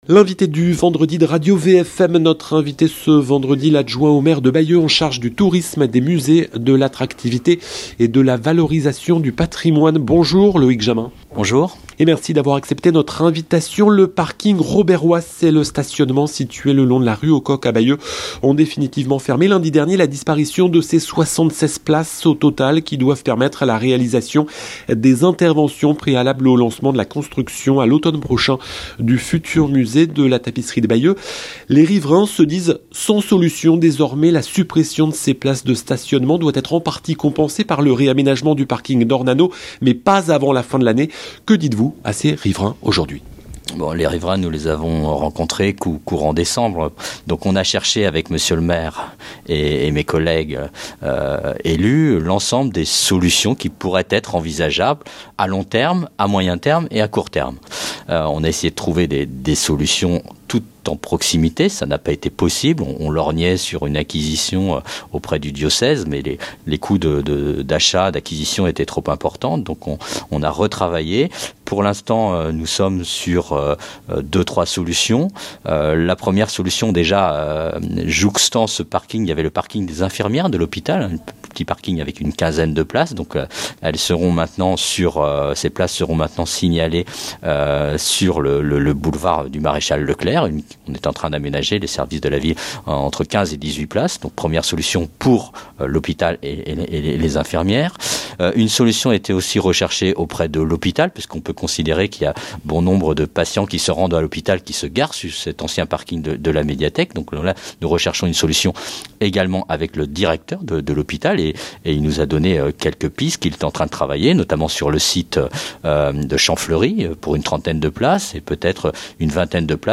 Loïc Jamin Loïc Jamin, l'adjoint au maire de Bayeux en charge du tourisme, des musées, de l'attravtivité et de la valorisation du patrimoine était l'invité de la rédaction de Radio VFM ce vendredi 17 janvier 2025, à 8 h 20.